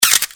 camera.mp3